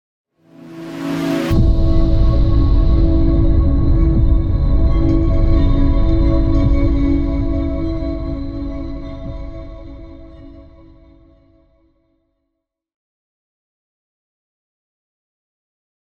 moonbeam-intro-v1-001.ogg